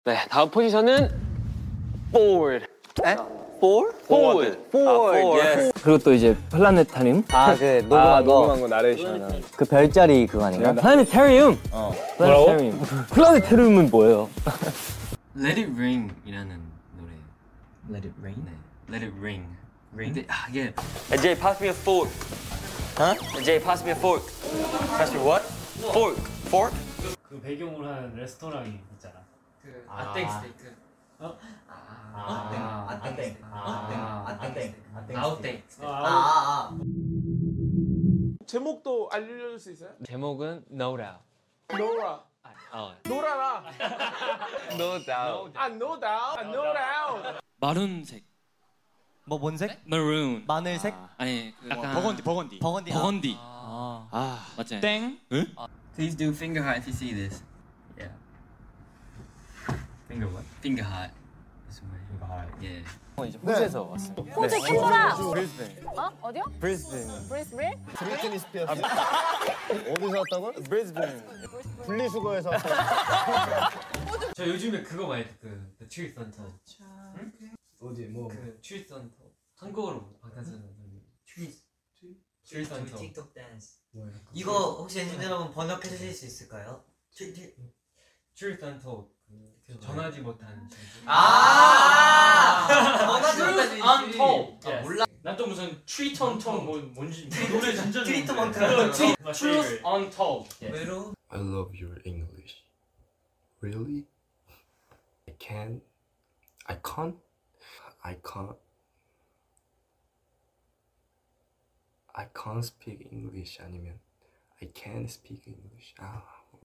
enhypen & other idols struggling to understand jake’s australian accent